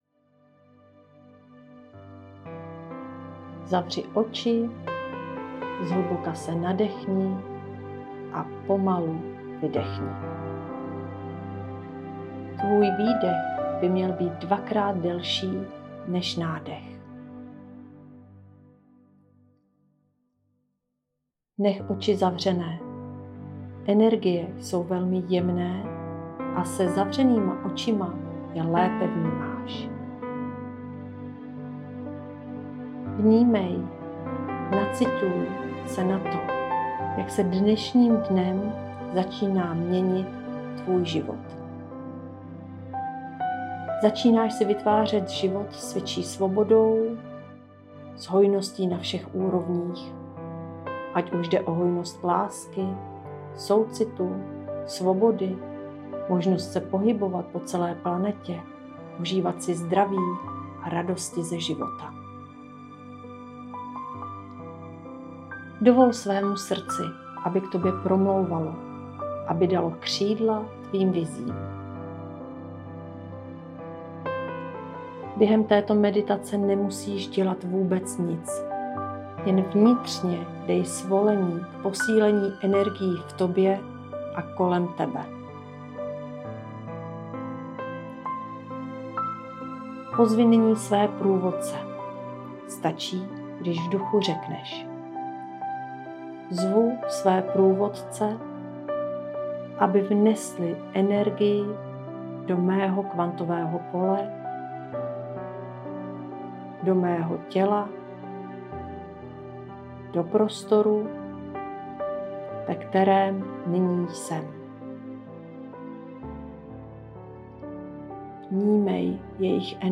Úvodní meditace
meditace.mp3